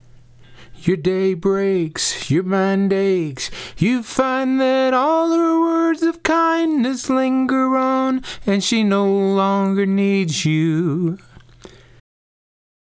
To prove my point, I have two vocals. The max initial level was -14.7dB below 0dBFS. On one track, I simply raised the fader by 14.7dB and rendered the file.